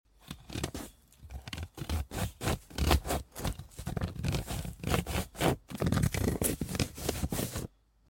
White Lemon Face Cutting 🔪🍋 Sound Effects Free Download